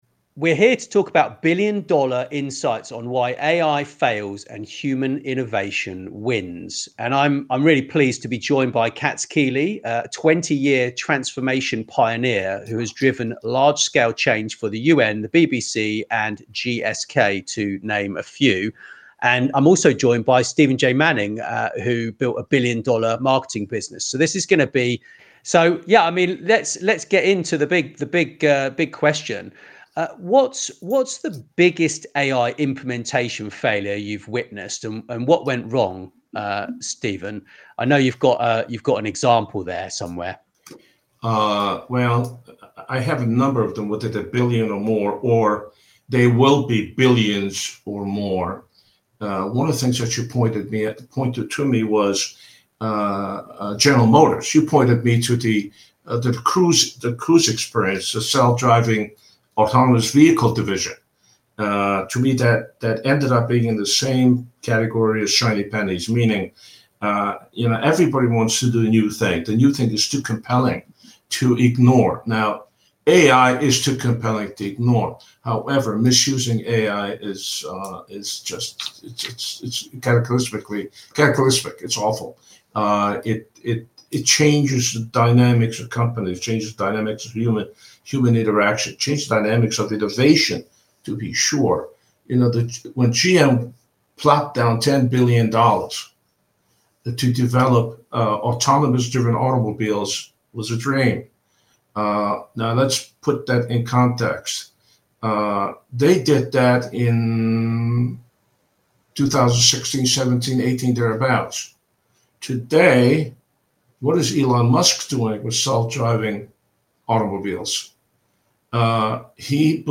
GM's Cruise, Babylon Health, IBM Watson failures reveal why human-centered transformation wins. Expert roundtable with billion-dollar insights.